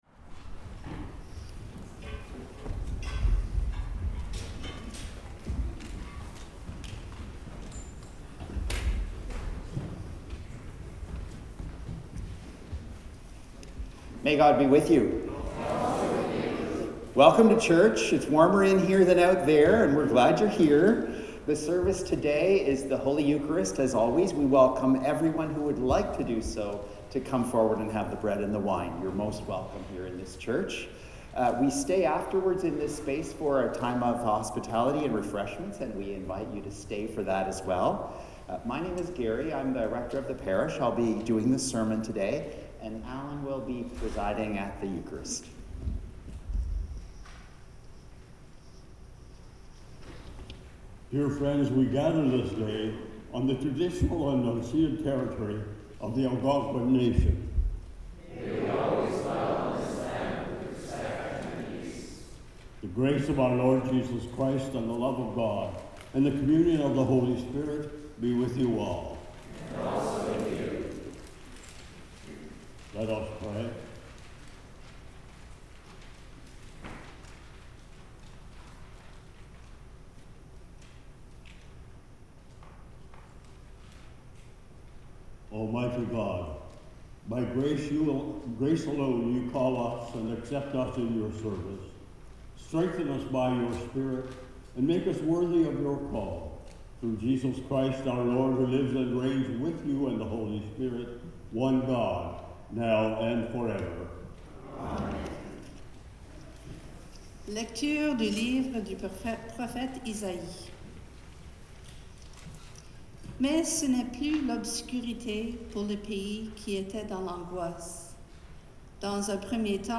Hymn 430: Will You Come and Follow Me
The Lord’s Prayer (sung)
Hymn 598: Go To The World